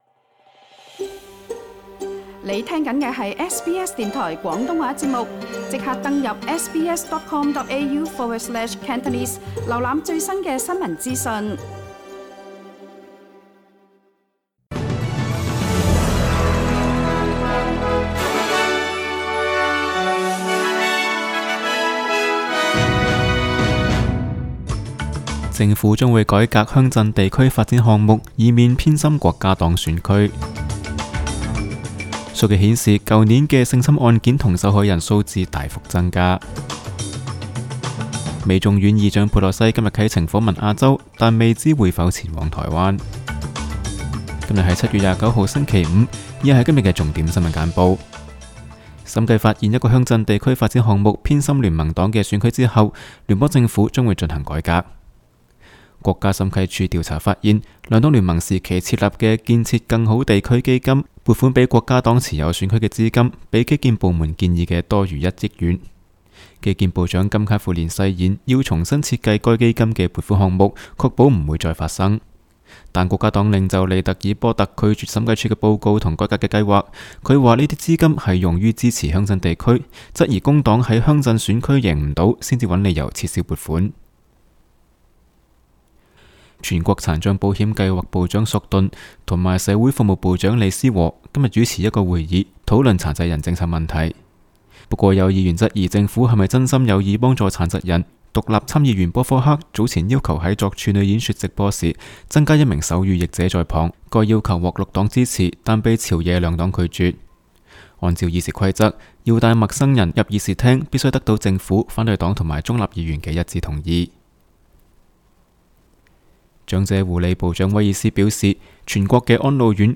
SBS 新闻简报（7月29日）
SBS 廣東話節目新聞簡報 Source: SBS Cantonese